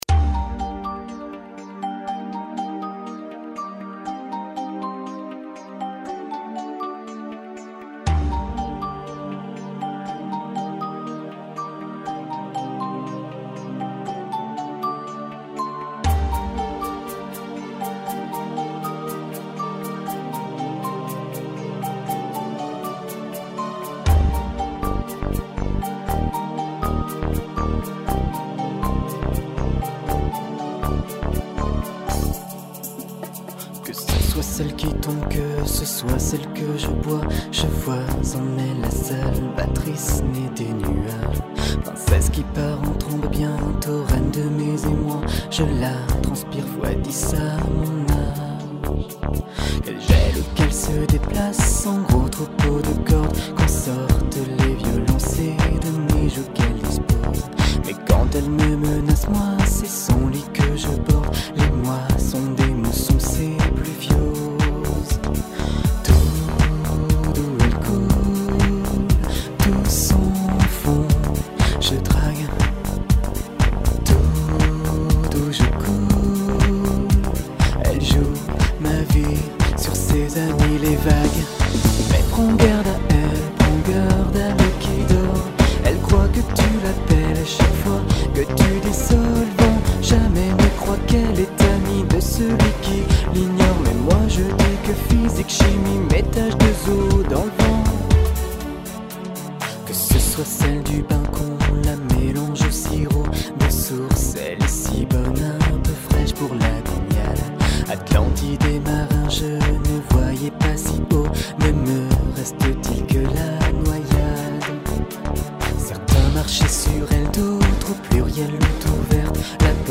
Par contre, le prélude est super! ;)